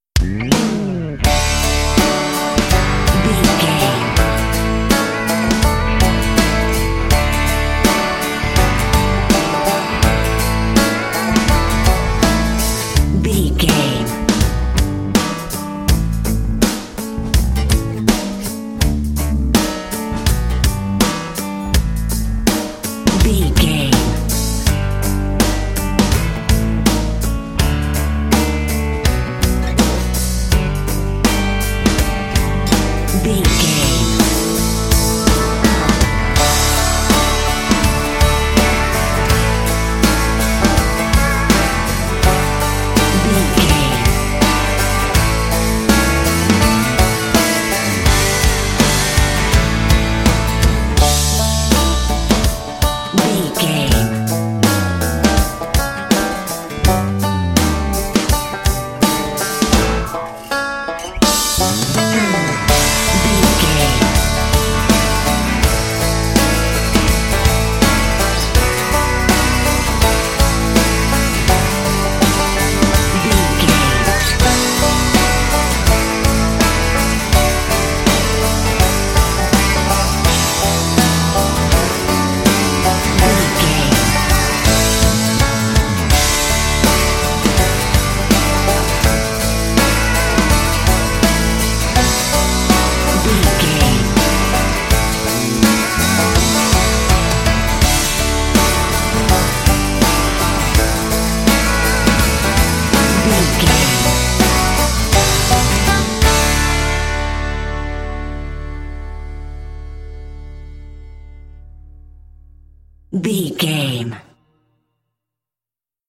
Ionian/Major
drums
electric piano
electric guitar
bass guitar
banjo
Pop Country
country rock
bluegrass
happy
uplifting
driving
high energy